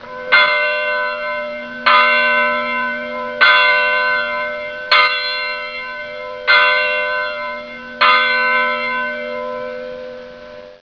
シスターの店 鐘　楼　(左)
午後0時、午後6時に、南山手一帯に鐘の音が響きます。